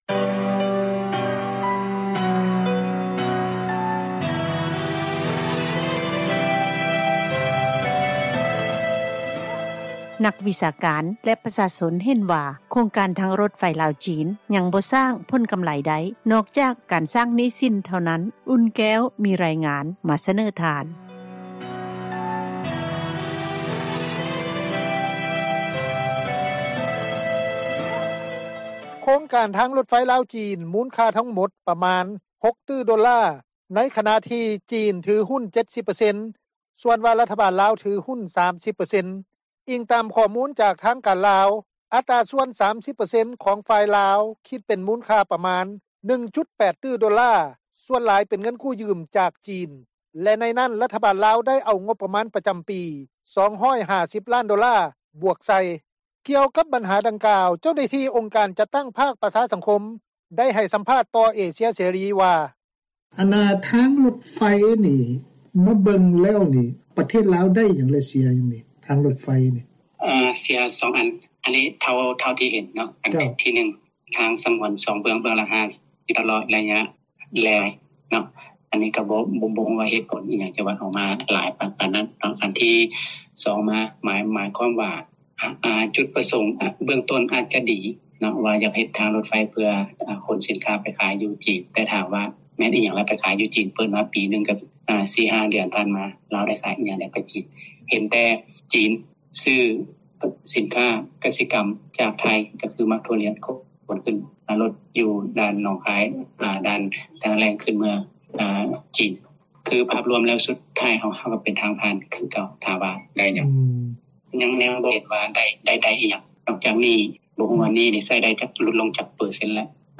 ກ່ຽວກັບບັນຫາດັ່ງກ່າວ, ເຈົ້າໜ້າທີ່ ອົງການຈັດຕັ້ງ ພາກປະຊາສັງຄົມ ໄດ້ໃຫ້ສໍາພາດຕໍ່ວິທຍຸ ເອເຊັຽເສຣີ ວ່າ:
ປະຊາຊົນທ່ານນີ້ ໄດ້ກ່າວຕໍ່ວິທຍຸ ເອເຊັຽເສຣີ ວ່າ:
ເຈົ້າໜ້າທີ່ ທາງການລາວ ທ່ານນຶ່ງ ໄດ້ກ່າວຕໍ່ວິທຍຸ ເອເຊັຽເຣີ ວ່າ: